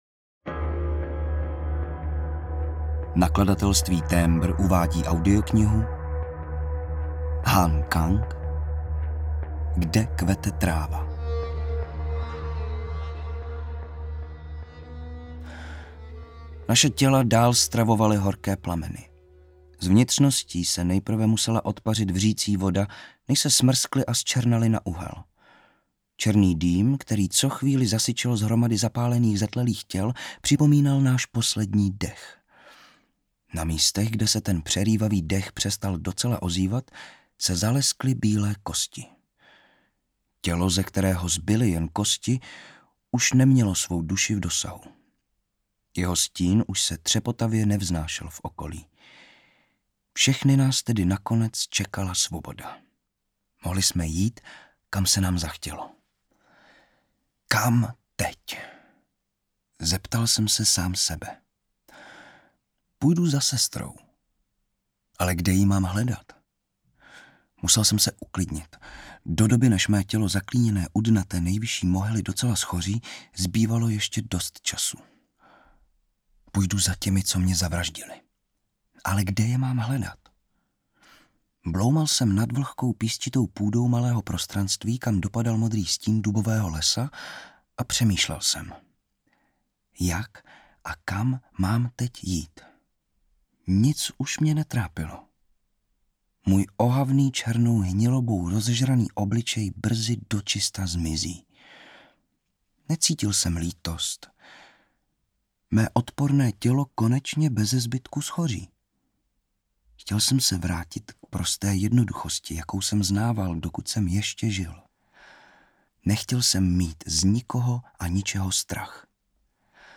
Audiobook
Read: Vanda Hybnerová